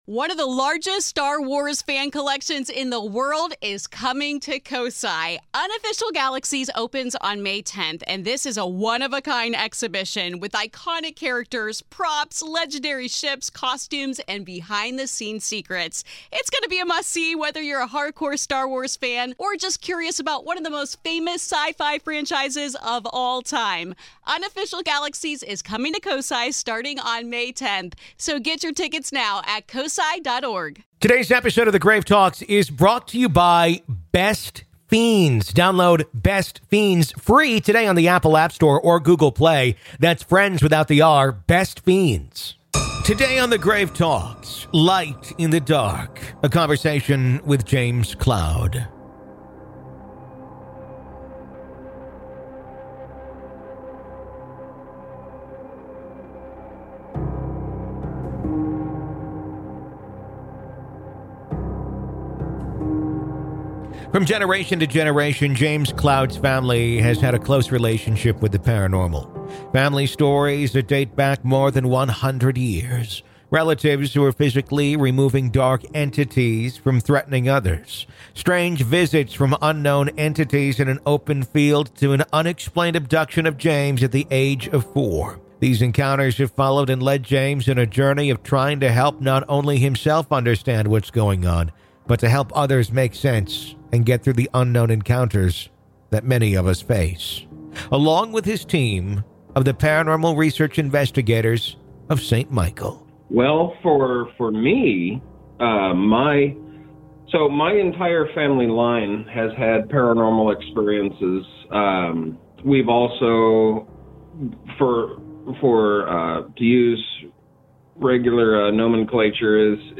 PART 2 - AVAILABLE TO GRAVE KEEPERS ONLY - LISTEN HERE In part two of our interview, available only to Grave Keepers , we discuss: Do all objects give off some sort of feeling or energy.